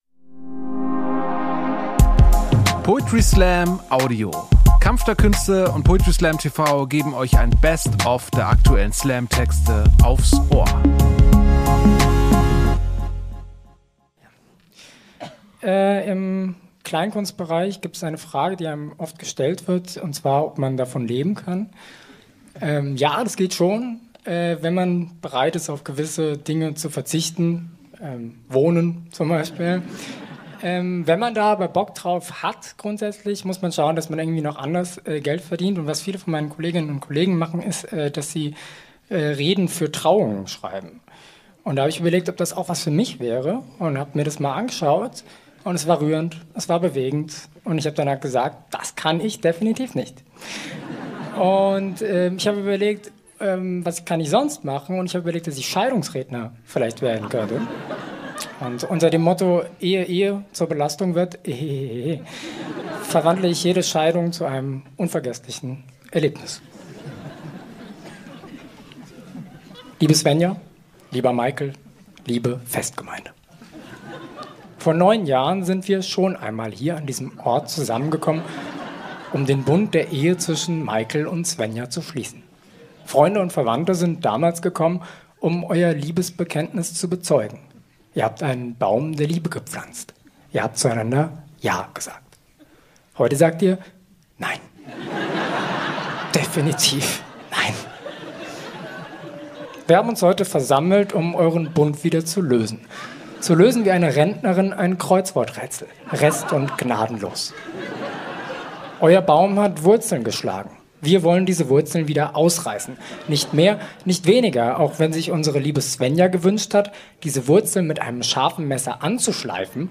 Stage: Ernst Deutsch Theater, Hamburg